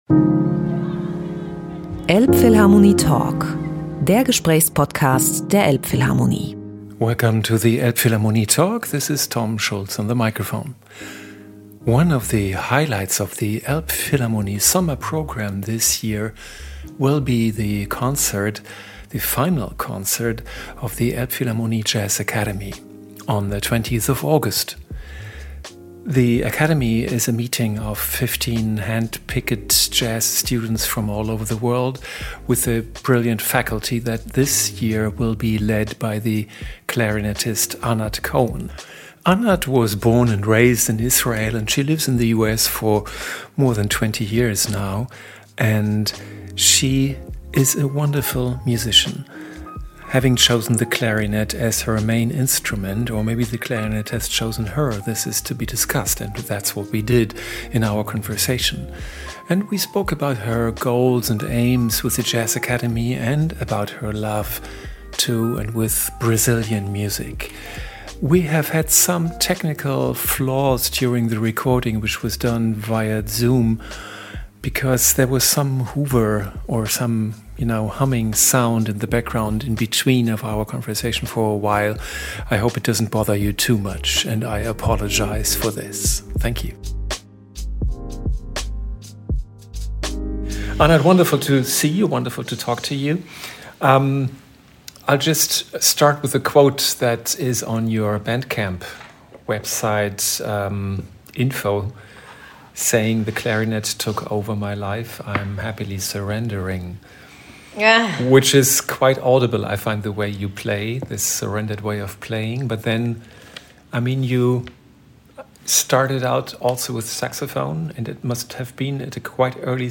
elbphilharmonie-talk-with-anat-cohen-mmp.mp3